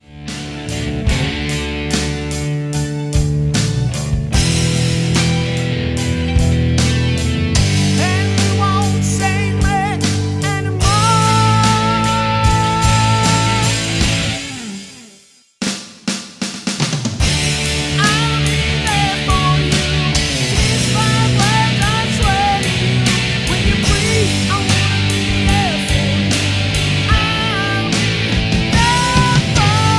MPEG 1 Layer 3 (Stereo)
Backing track Karaoke
Rock, 2000s